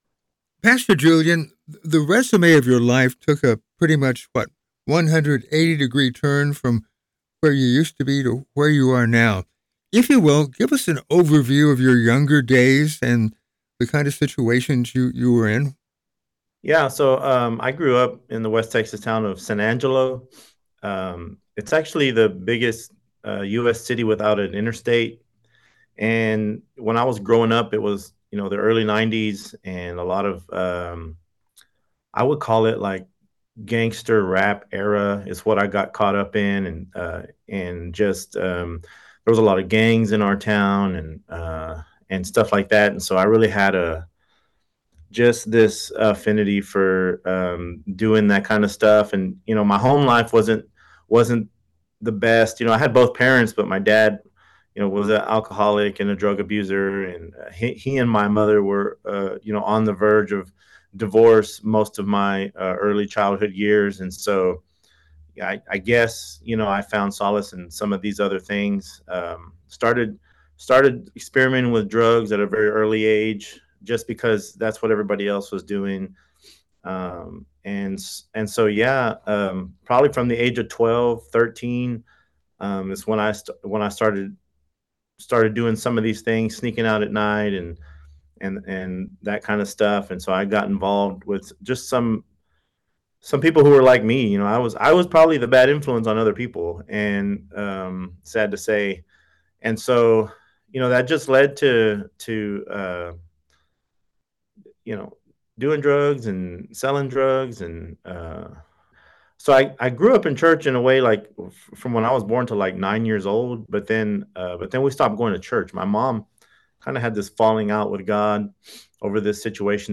(Rather listen? Here's our full encouraging interview:)